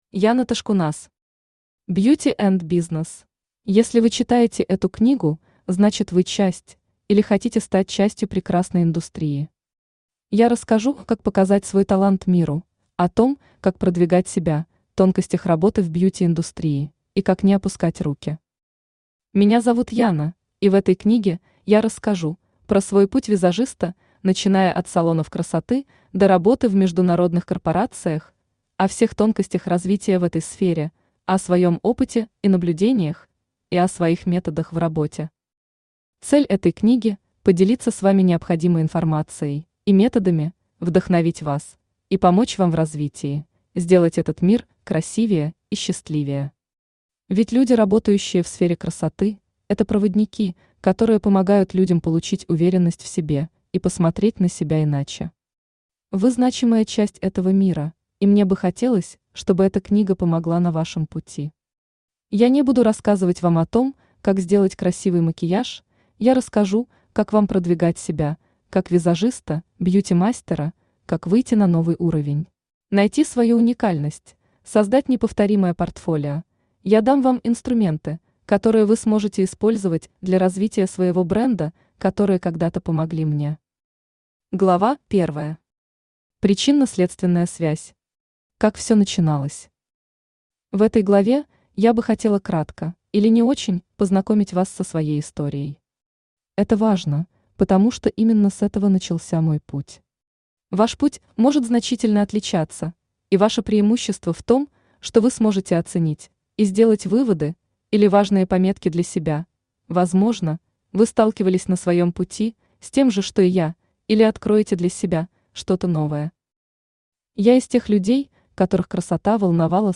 Aудиокнига Beauty & Business Автор Яна Ташкунас Читает аудиокнигу Авточтец ЛитРес.